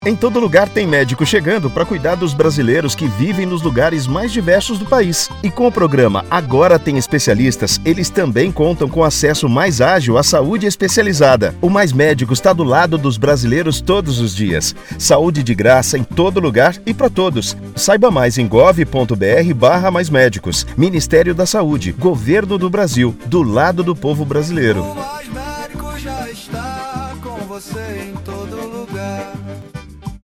Áudio - Spot 30s - Mais Médicos - 596kb .mp3 — Ministério da Saúde